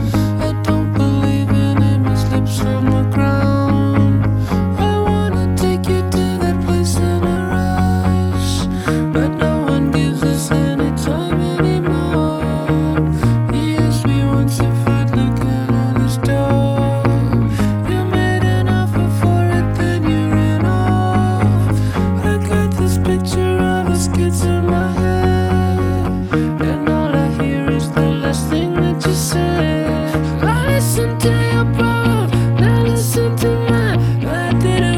Жанр: Танцевальные / Электроника